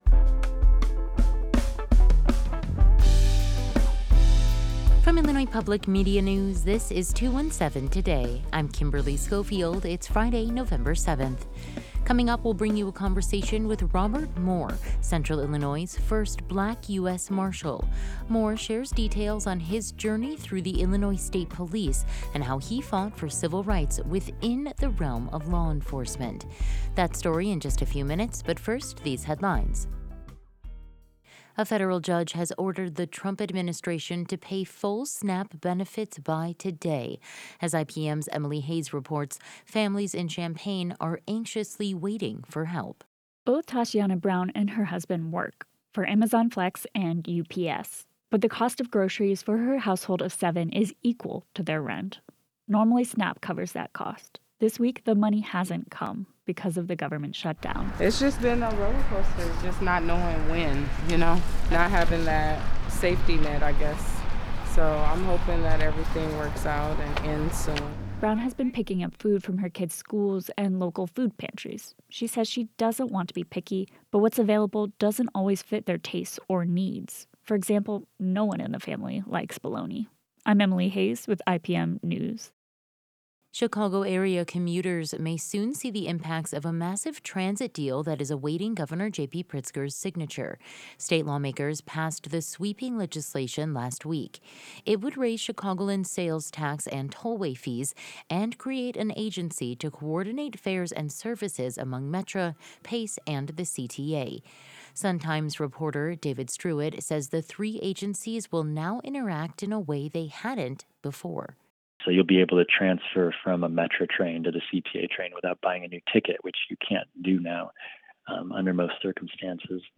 In today’s deepdive, we'll bring you a conversation with Robert Moore Central Illinois' first Black U.S. Marshal. Moore shares details on his journey through the Illinois State Police and how he fought for civil rights within the realm of law enforcement.